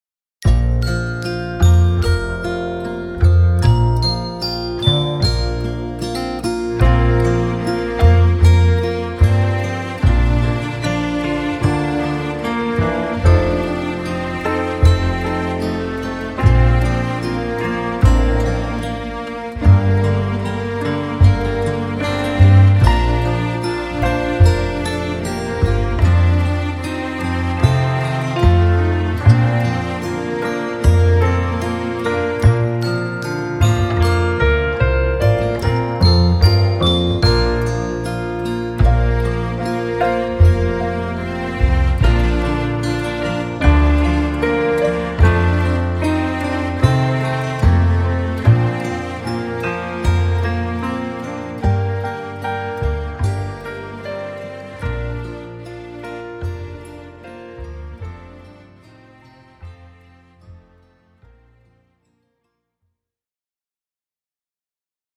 Latviešu tautas dziesma Play-along.
Spied šeit, lai paklausītos Demo ar melodiju